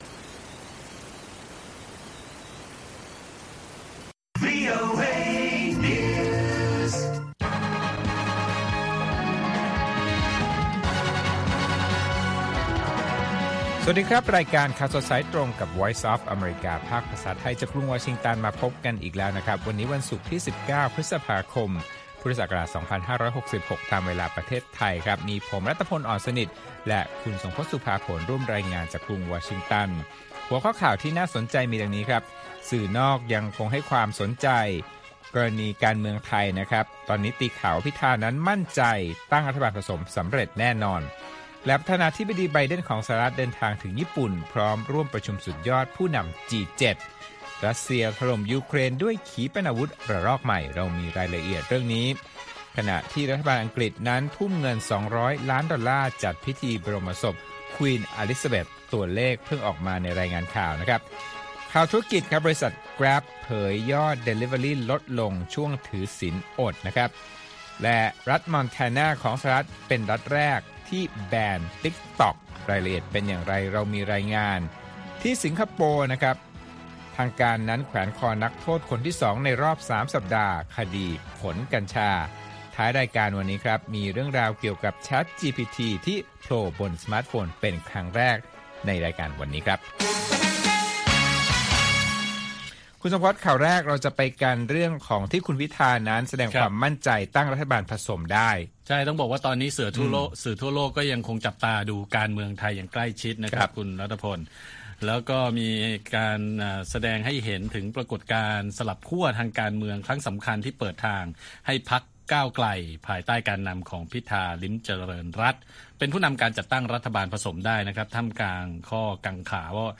ข่าวสดสายตรงจากวีโอเอไทย 8:30–9:00 น. วันที่ 19 พฤษภาคม 2566